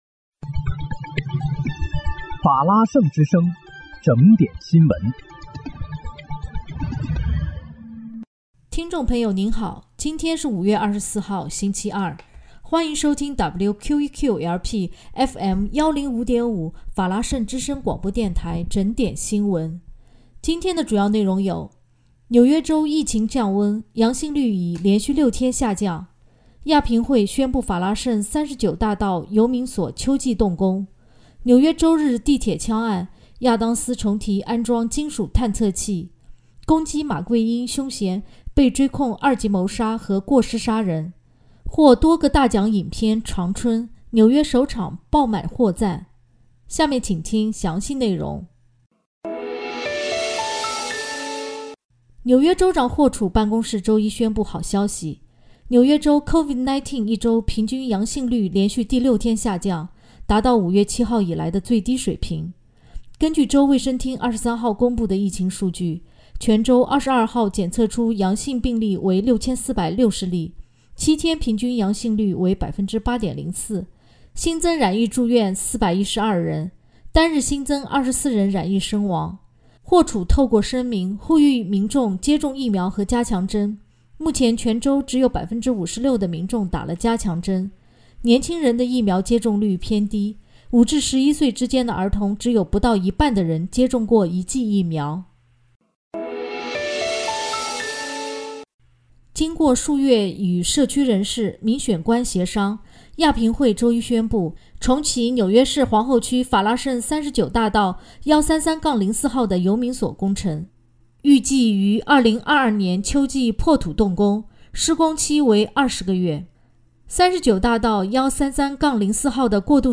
5月24日（星期二）纽约整点新闻